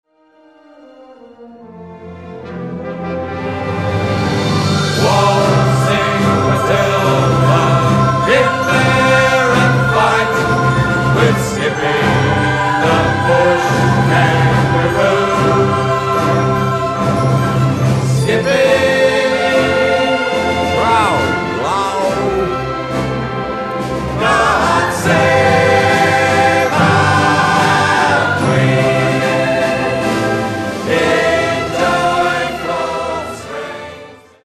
Guitar, Vocals
Double Bass, Vocals
Banjo, Vocals